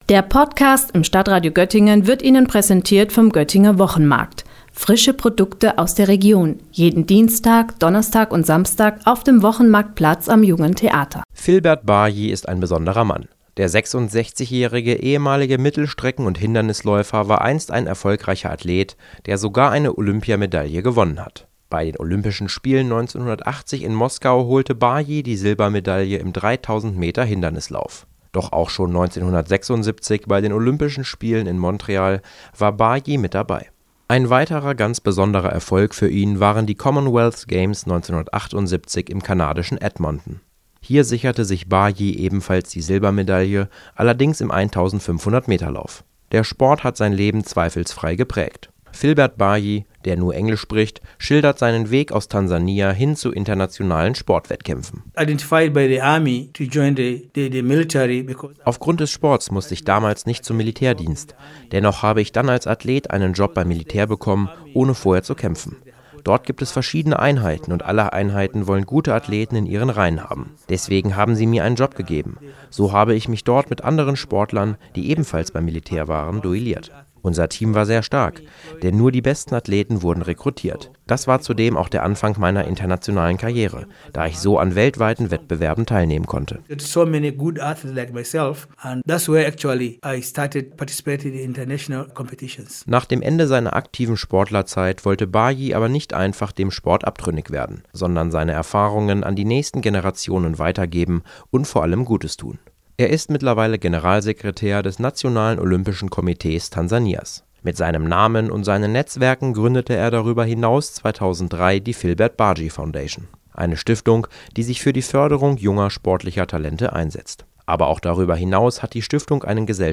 Beiträge > Filbert Bayi – Ein Olympia-Medaillen-Gewinner und Stiftungsgründer im Gespräch - StadtRadio Göttingen